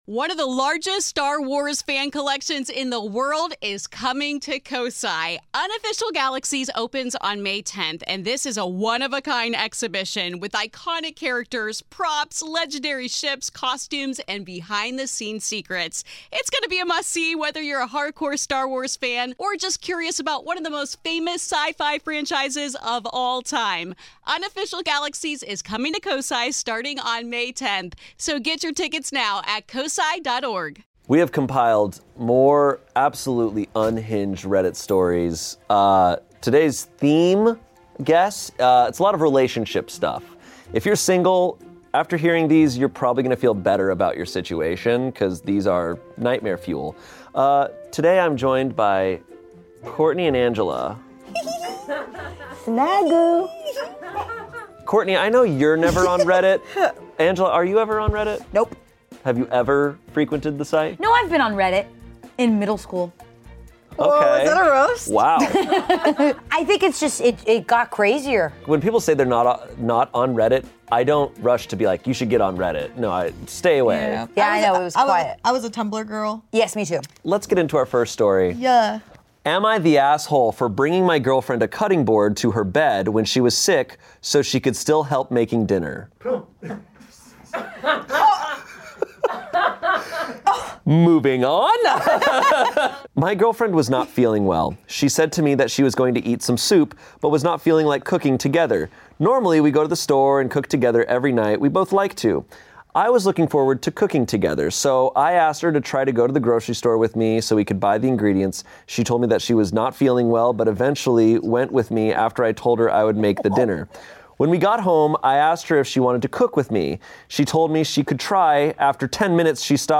Relationships Gone Wrong | Reading Reddit Stories